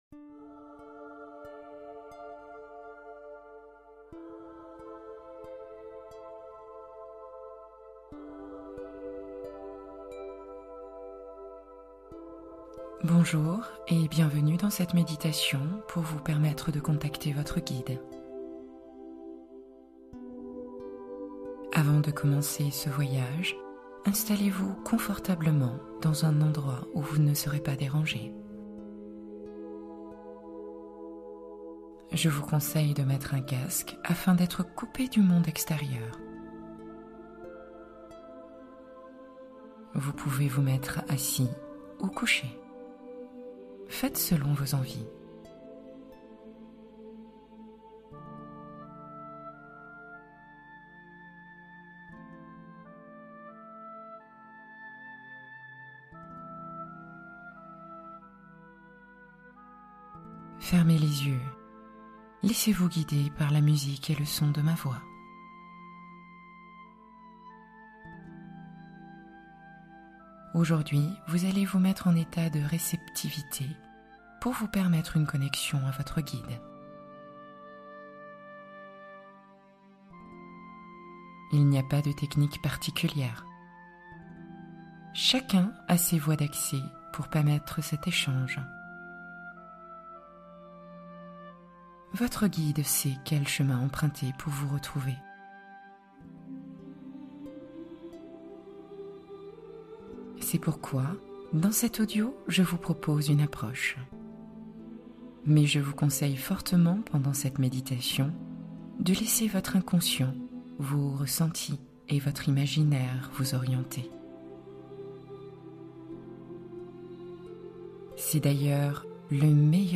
Éveiller son soleil intérieur : méditation matinale pour dynamiser l’élan vital